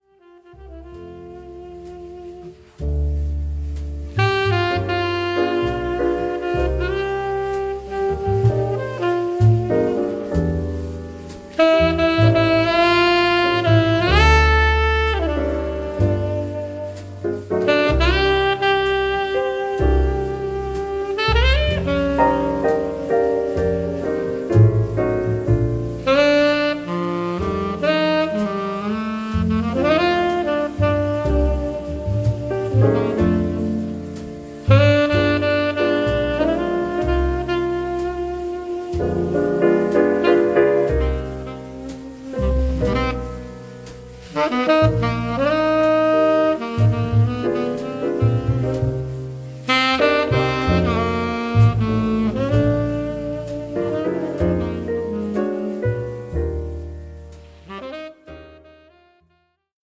ゆったりとやすらぎのあるサックス・プレイ、バックの演奏が楽しめる1枚です。